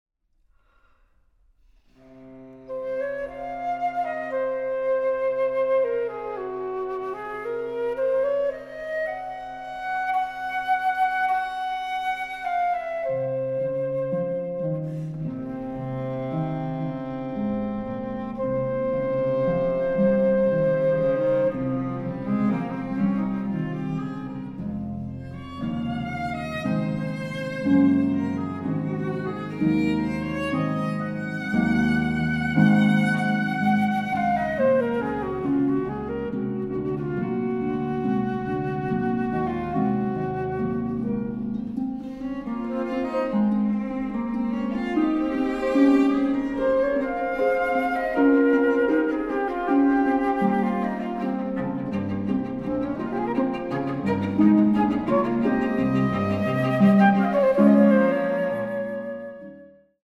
Flöte
Violoncello
Harfe